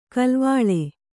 ♪ kalvāḷe